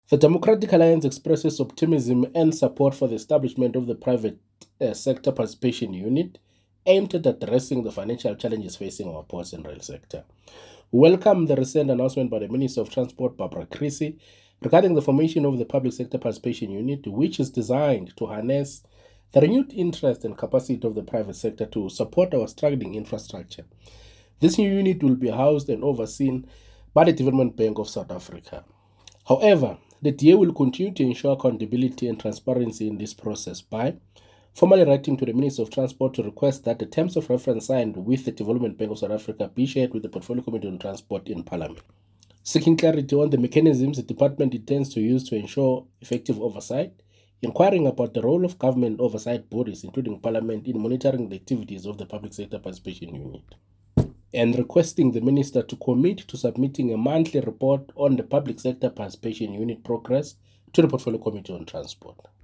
soundbite by Thamsanqa Mabhena MP.